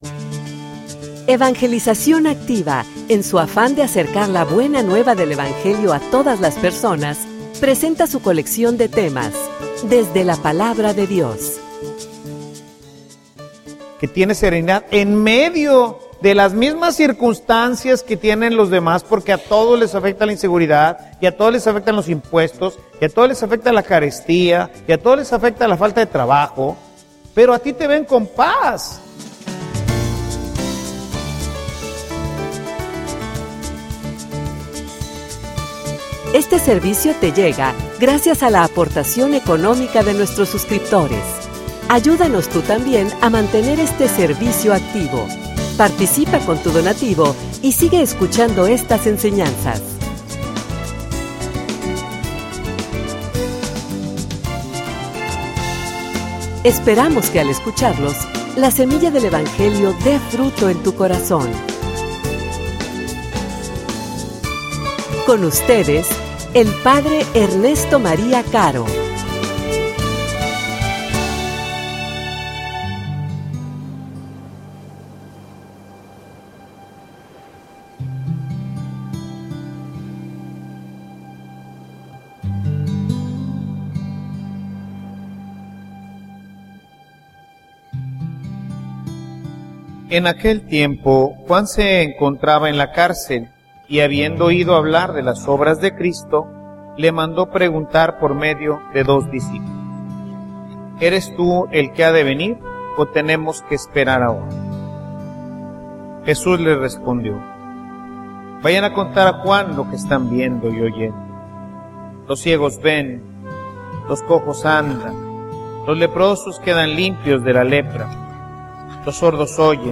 homilia_Es_la_iglesia_catolica_el_camino_a_la_felicidad.mp3